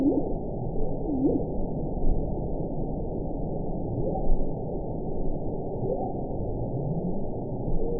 event 922315 date 12/29/24 time 21:29:08 GMT (5 months, 3 weeks ago) score 9.67 location TSS-AB10 detected by nrw target species NRW annotations +NRW Spectrogram: Frequency (kHz) vs. Time (s) audio not available .wav